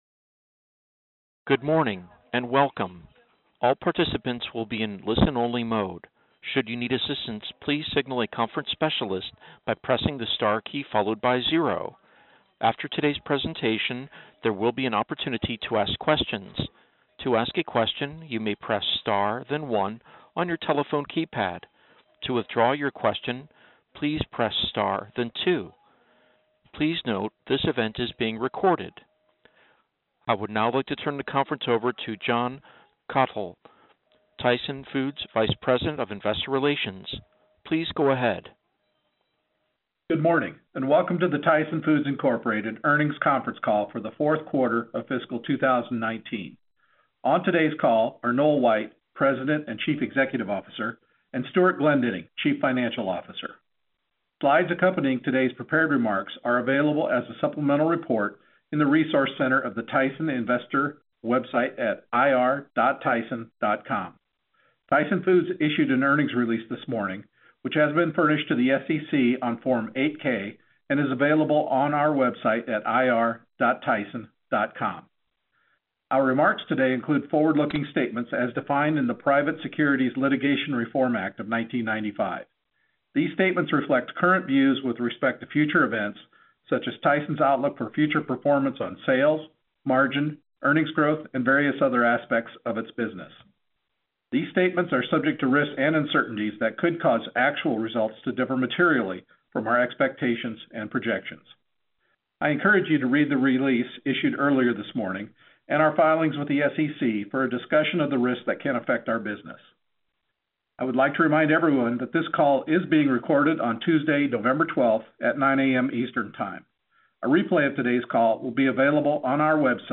Tyson Foods Inc. - Q4 2019 Tyson Foods Earnings Conference Call